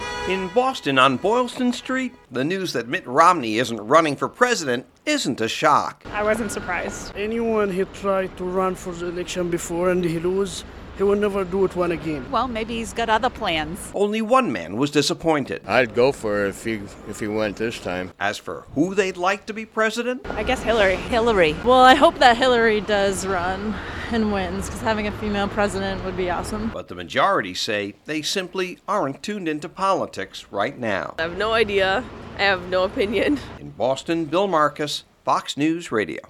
(BOSTON) JAN 30 – VOTERS IN THE LARGEST CITY OF THE STATE WHERE HE WAS ONCE GOVERNOR REACTED FRIDAY TO MITT ROMNEY SAYING HE WAS NOT RUNNING FOR PRESIDENT.